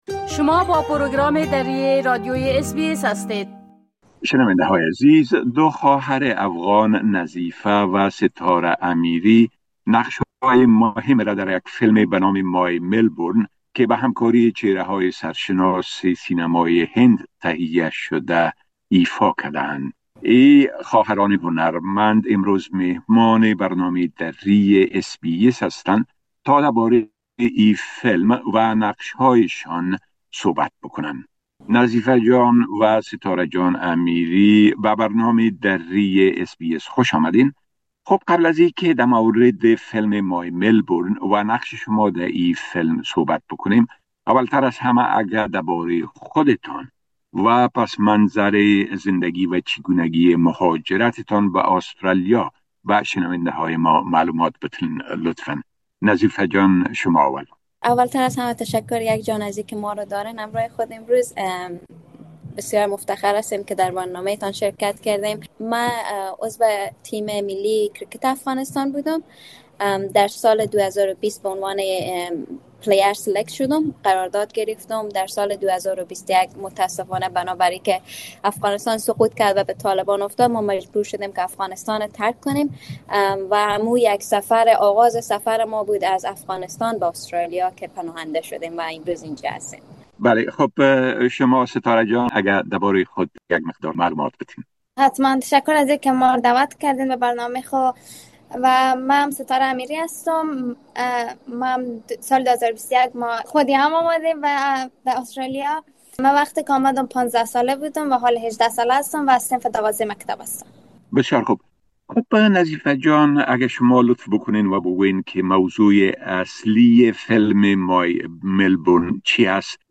ما با اين خواهران هنرمند مصاحبه كرده ايم كه شما را به شنيدن آن دعوت مى كنيم.